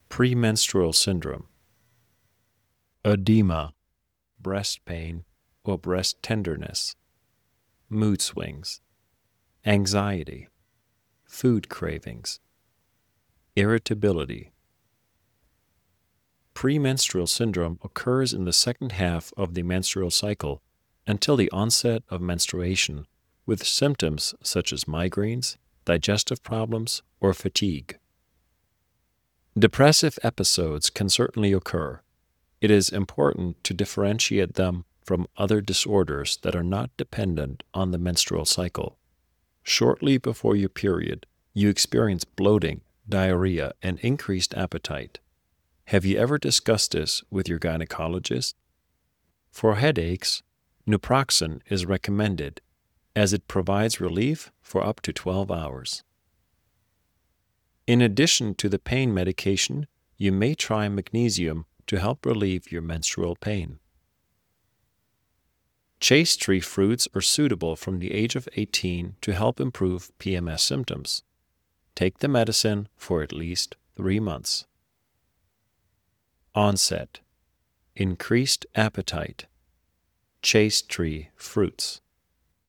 In dieser Englisch-Lerneinheit vermitteln wir Ihnen Wörter, die Sie rund um das Beratungsgespräch verwenden können, wenn es um das Thema Nasenbluten geht. Hören Sie in unserer Audiodatei, wie die englischen Wörter ausgesproc...